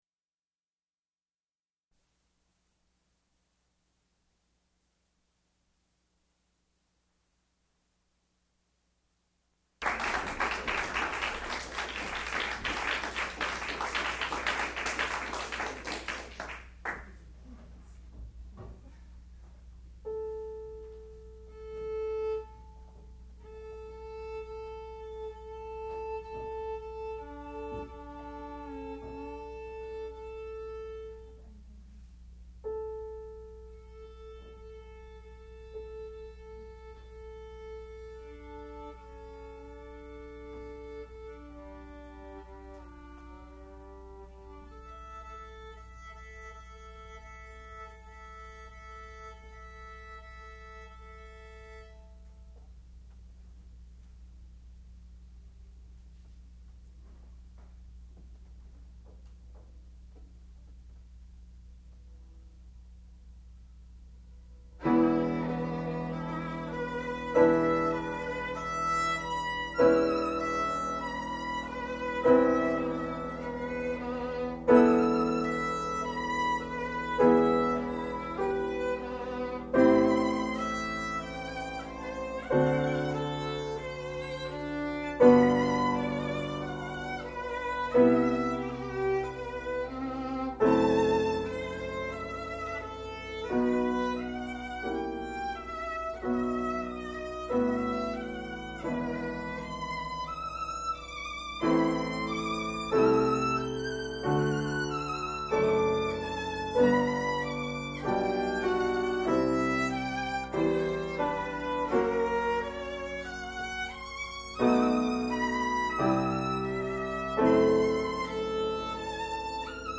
sound recording-musical
classical music
violin
piano
Advanced Recital